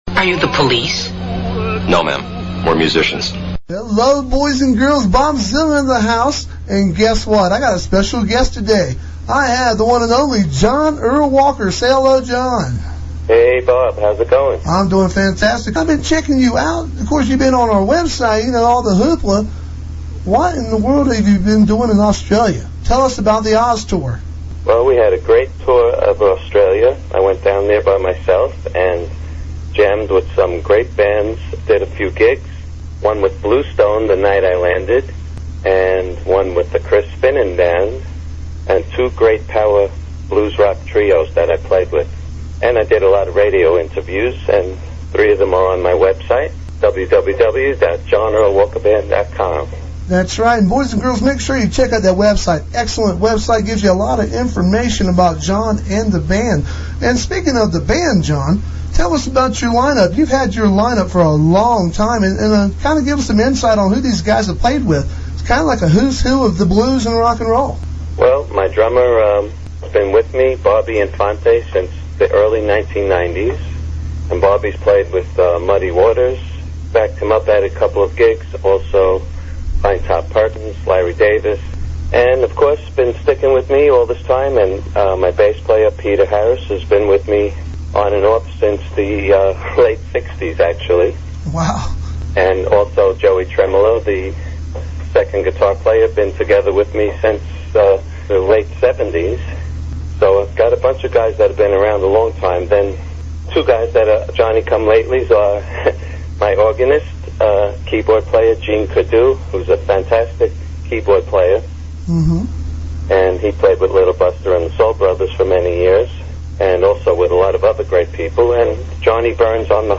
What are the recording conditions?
A live phone interview